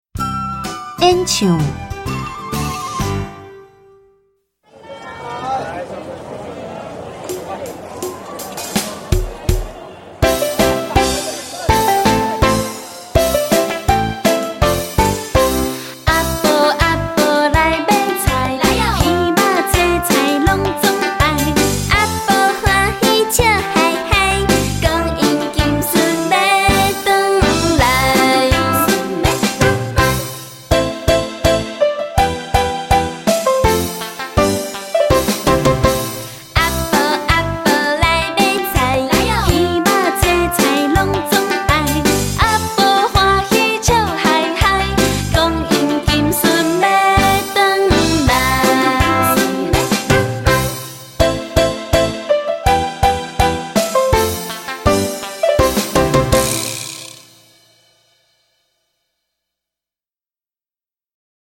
第四課演唱